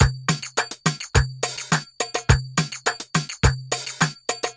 阿拉伯鼓点节奏
描述：阿拉伯鼓点节奏。